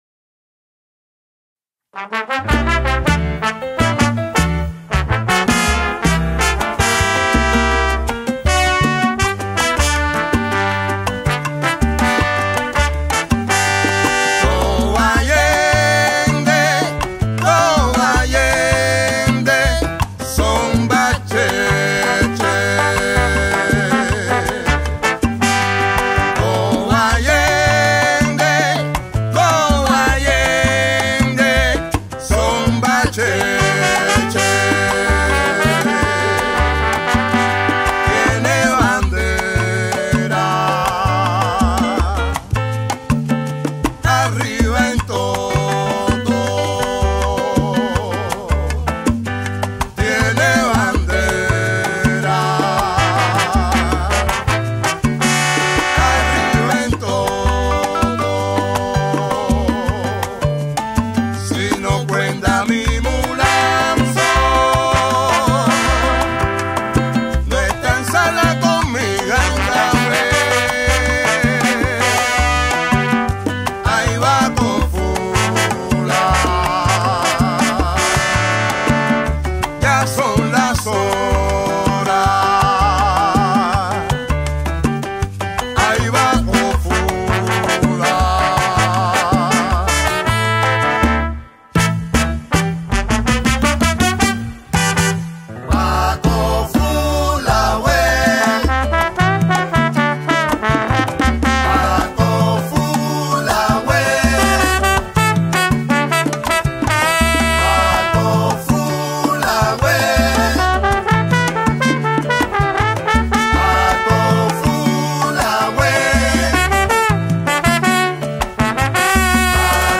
Salsa descargas